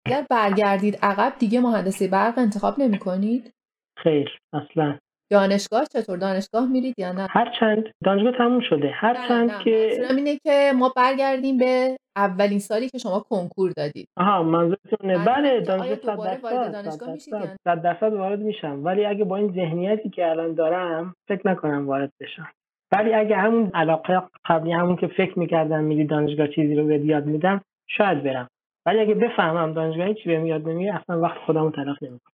داستان موفقیت.مصاحبه
انگار من از ته چاه داد بزنم و او ایستاده باشد تا دو-سه ثانیه بعد صدایم برسد بالا به دهانه.
نسخه صوتی مصاحبه (فایل صوتی) به صورت مباحث جداگانه